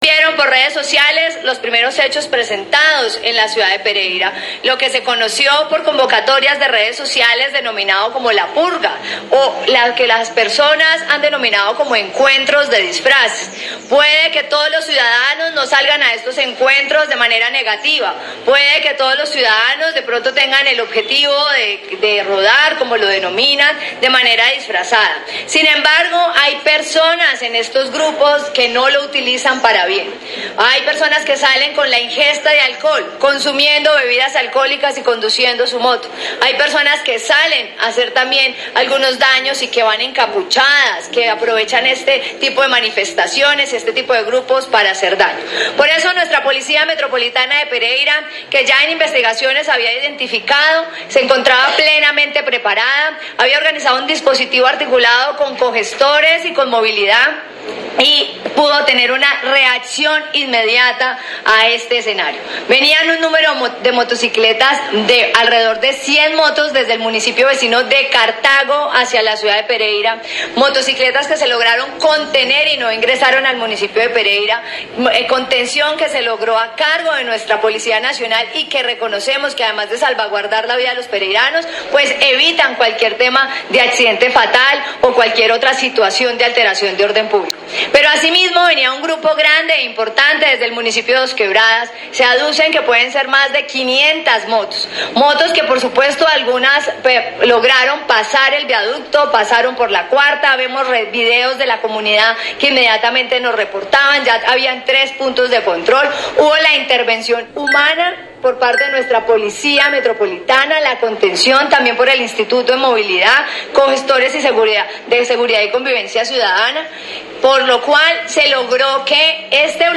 La Secretaria de Gobierno de Pereira, Karen Zape Ayala, informó en rueda de prensa a los medios de comunicación, sobre los resultados la noche anterior de la Policía Metropolitana de Pereira, que evitó que un gran número de motociclistas irrumpieran en la ciudad para realizar la llamada “purga”.